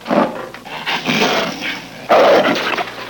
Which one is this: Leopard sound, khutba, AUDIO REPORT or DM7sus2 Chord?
Leopard sound